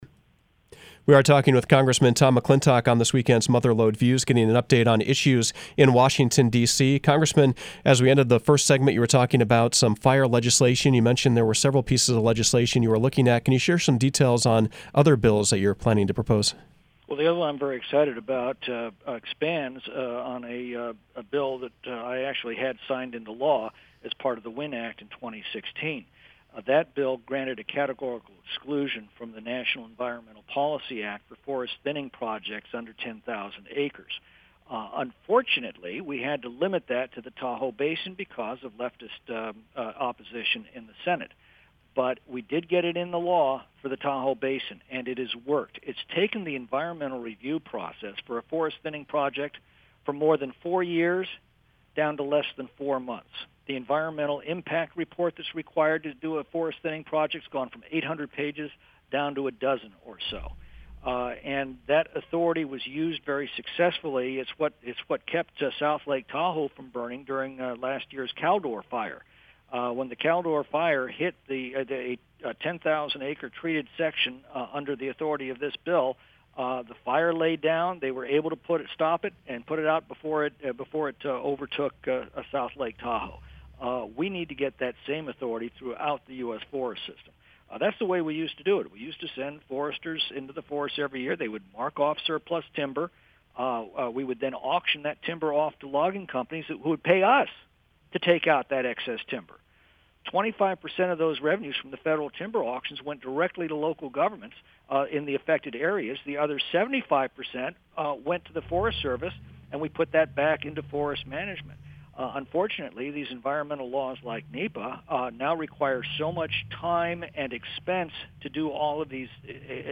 Mother Lode Views featured Republican Congressman Tom McClintock. He weighed in on issues like inflation, high energy prices and the Russia Ukraine conflict. He also previewed federal fire fighting legislation that he is introducing ahead of the busy summer fire season.